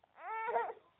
crying